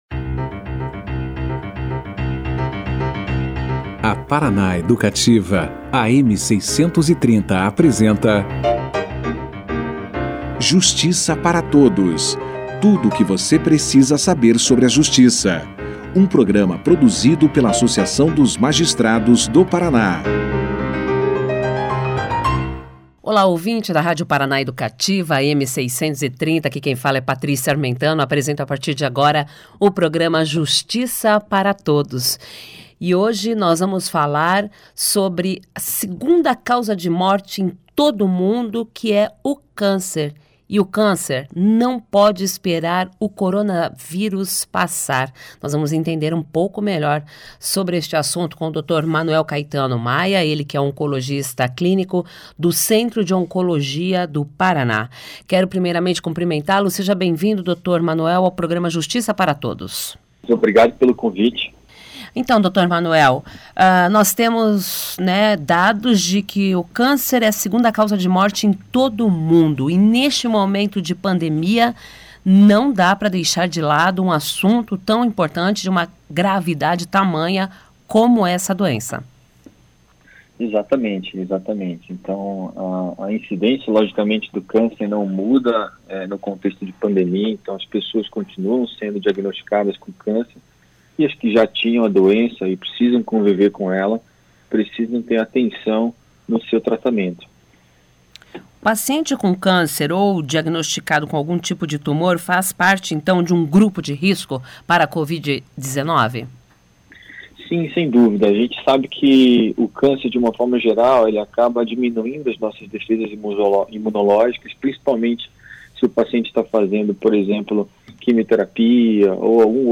>>Clique Aqui e Confira a Entrevista na Integra<<